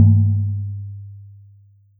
boing.wav